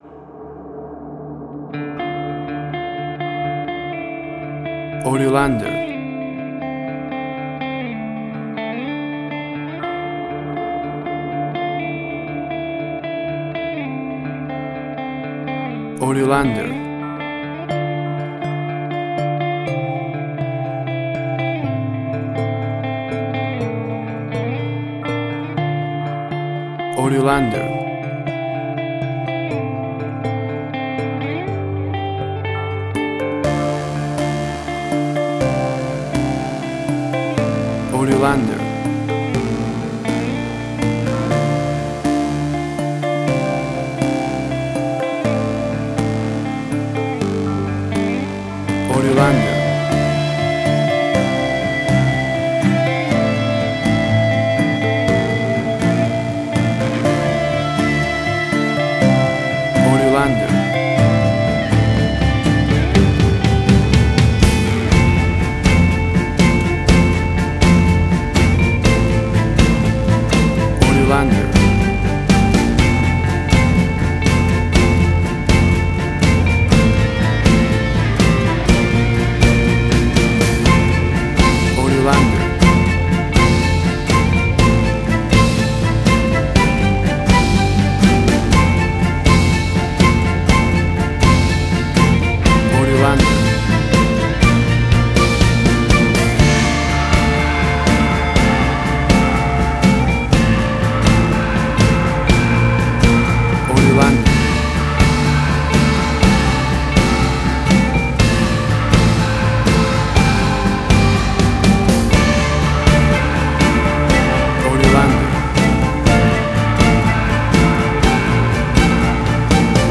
Suspense, Drama, Quirky, Emotional.
Tempo (BPM): 122